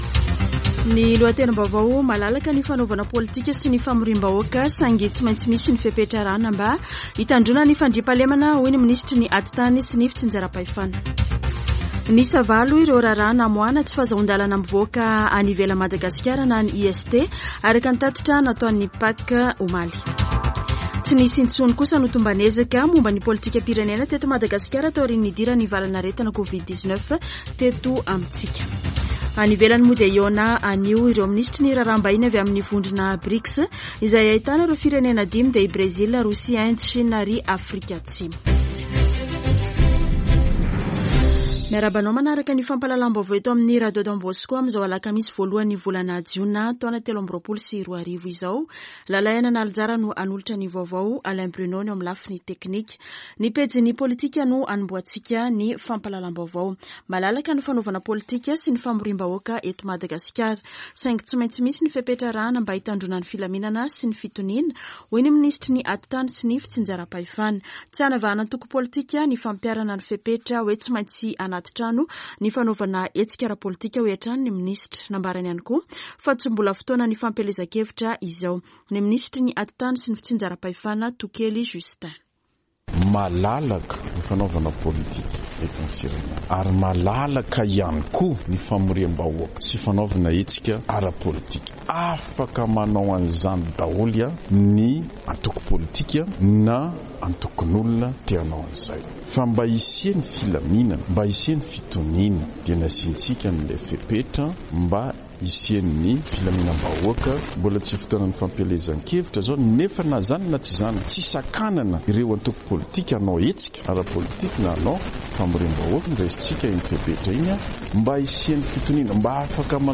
[Vaovao maraina] Alakamisy 1 jona 2023